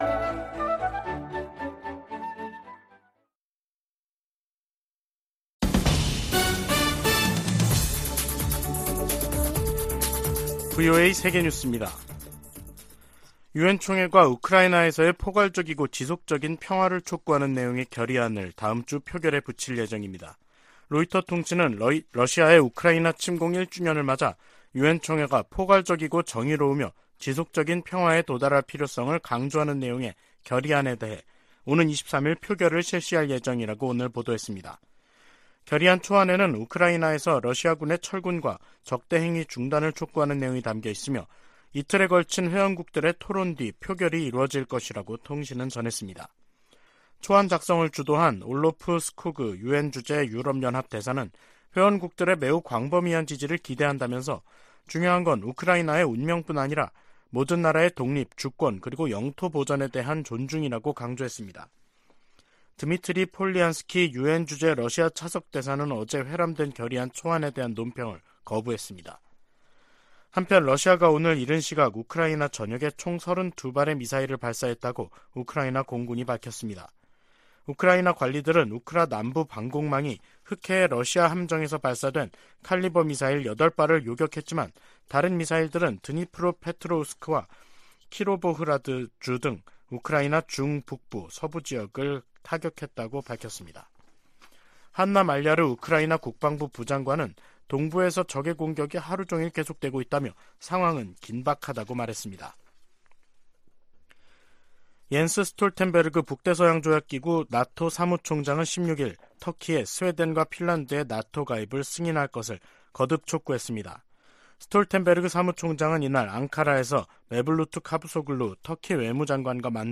VOA 한국어 간판 뉴스 프로그램 '뉴스 투데이', 2023년 2월 16일 3부 방송입니다. 한국 정부의 국방백서가 6년만에 북한 정권과 군을 다시 적으로 명시했습니다. 미 국무부의 웬디 셔먼 부장관이 한국, 일본과의 외교차관 회담에서 북한의 도발적인 행동을 규탄하고 북한의 외교 복귀를 촉구했습니다. 미 하원에서 다시 재미 이산가족 상봉 결의안이 초당적으로 발의됐습니다.